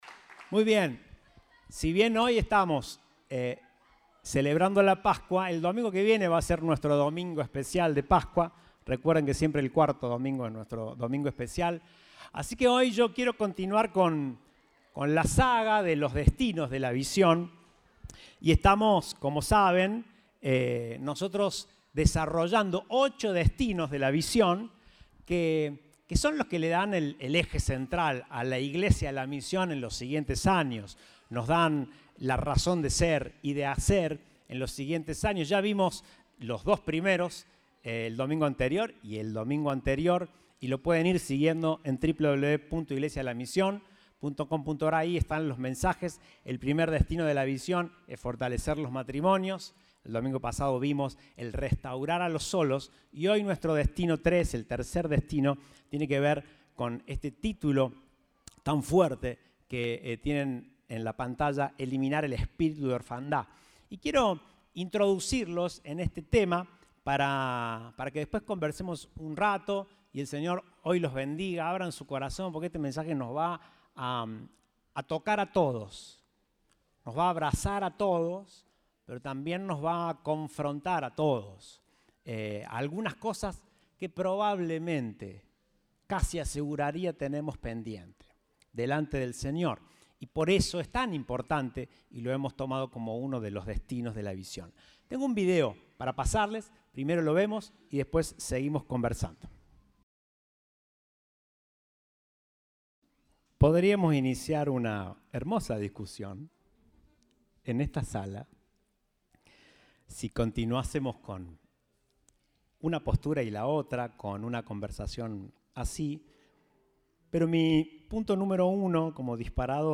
Compartimos el mensaje del Domingo 17 de Abril de 2022.